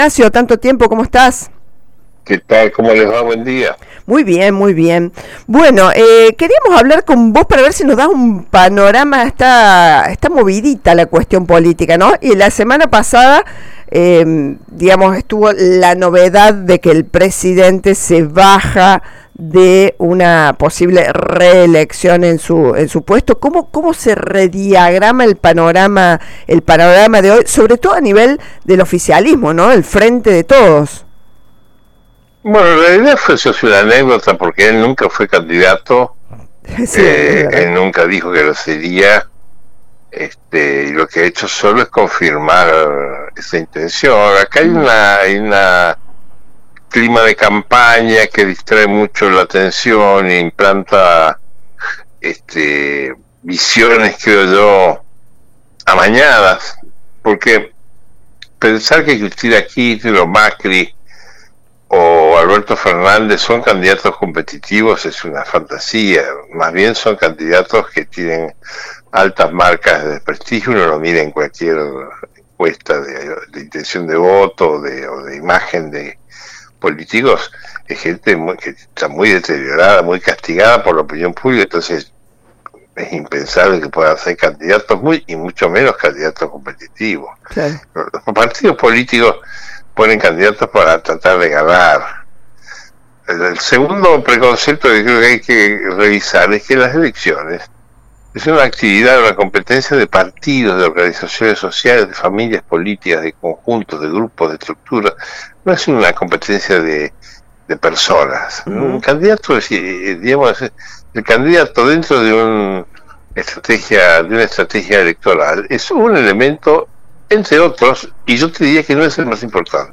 CRUELDADES DE LA POLÍTICA: DISCUTIR SOBRE CANDIDATOS PARA PERDER (Un diálogo por radio)